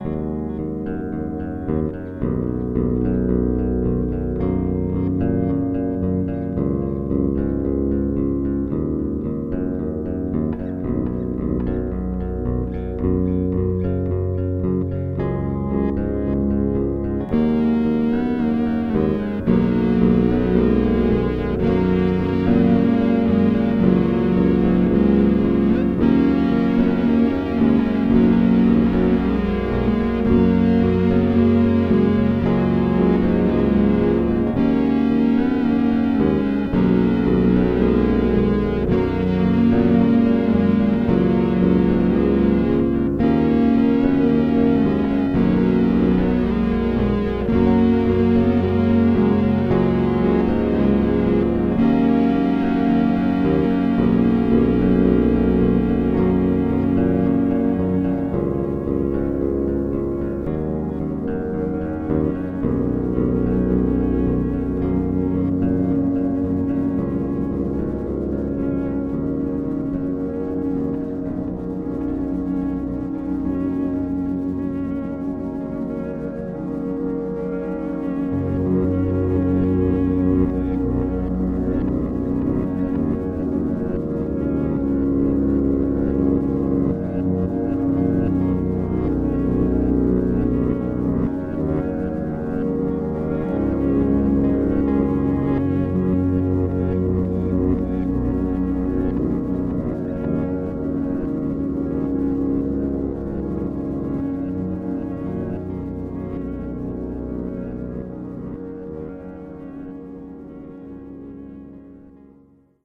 A loose canon for electric bass and fuzz pedal.